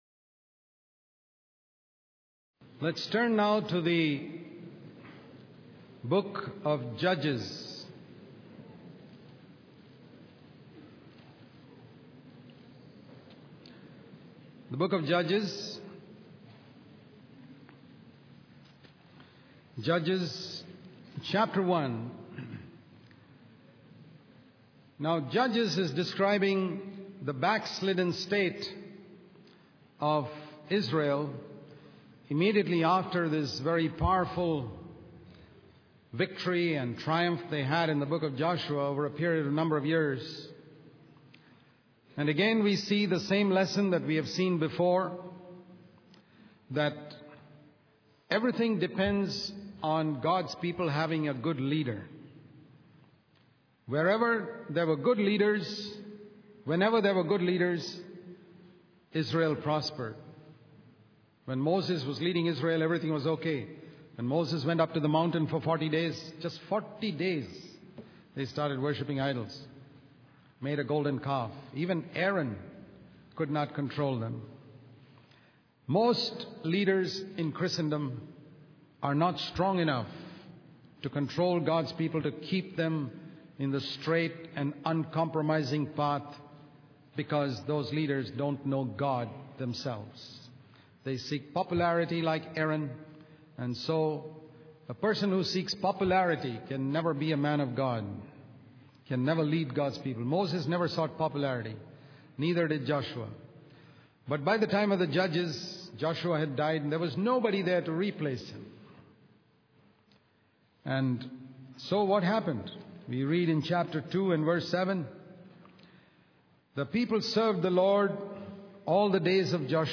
In this sermon, the preacher focuses on the book of Judges in the Bible, specifically chapters 17 to 21. These chapters highlight the prevalent idolatry, immorality, and wars among the Israelites.